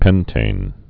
(pĕntān)